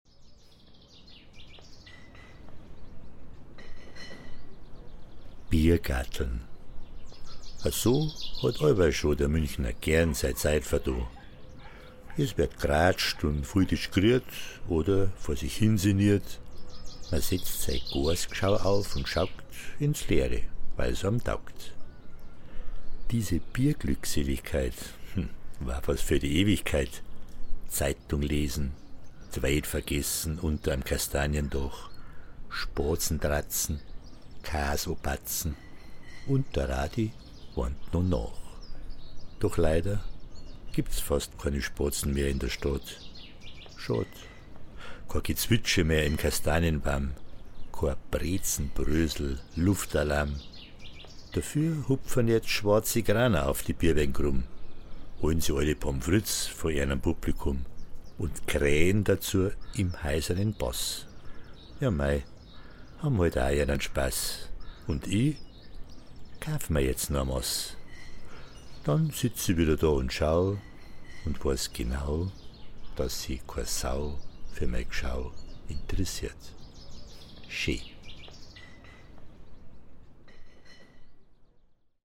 Inspiriert von vielen Anekdoten, besonders auf und hinter der Bühne, entstand so im Laufe der Zeit eine kleine Sammlung von Kurzgeschichten und Mundartgedichten, die nach und nach in diesem Podcast zu hören sein werden - vui Spass!